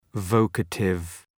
{‘vɒkətıv}